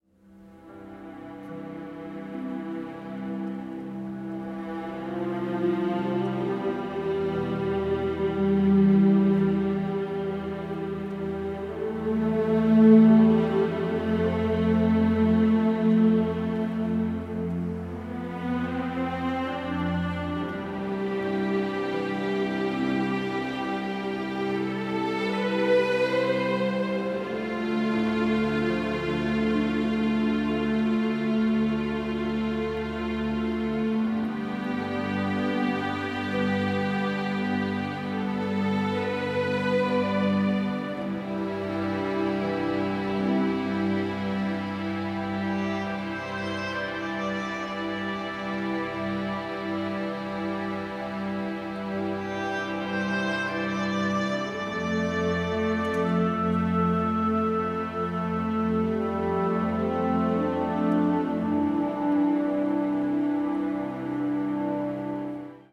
ORCHESTRA SUITE: